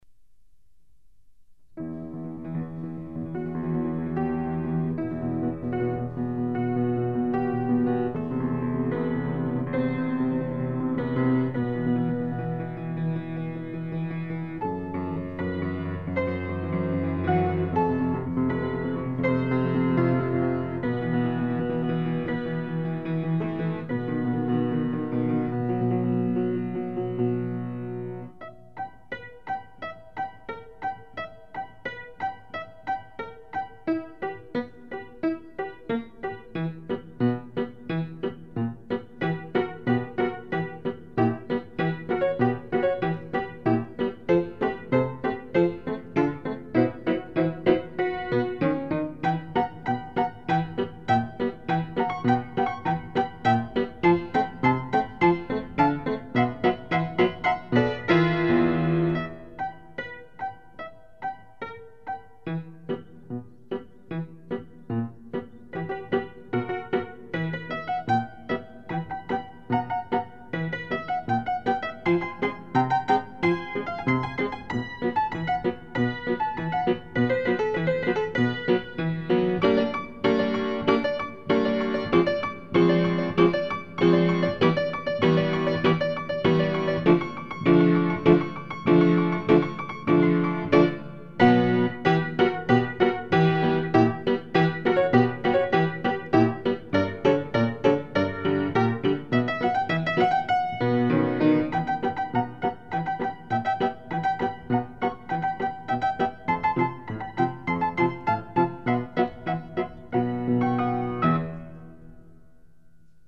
pianoforte); Goblins